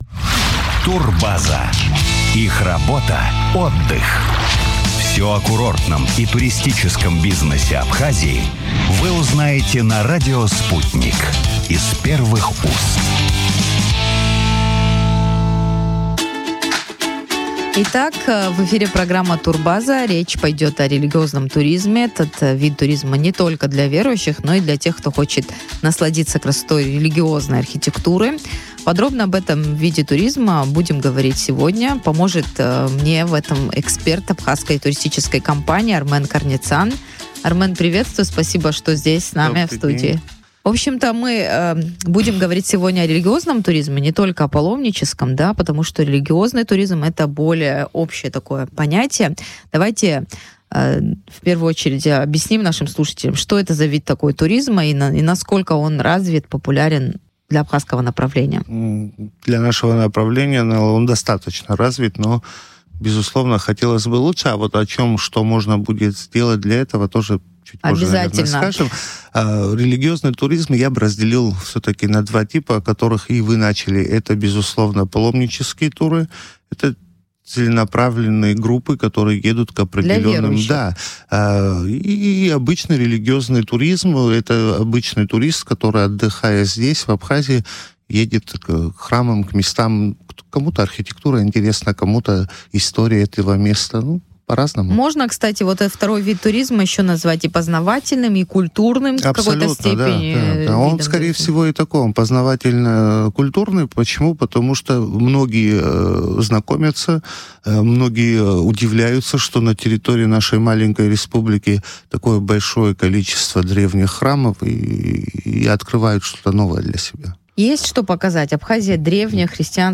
в эфире радио Sputnik